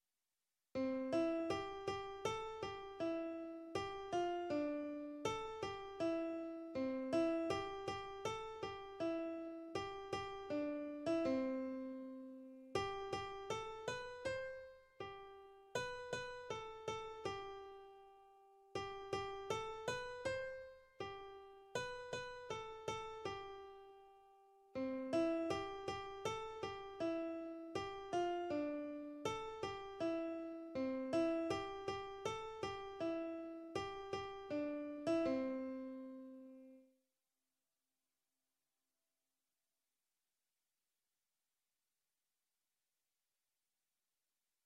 Little Redbird In The Tree (C Major)
This simple, happy song is great for: * Teaching ternary form and the terms 'Fine' and 'D.C. al Fine'.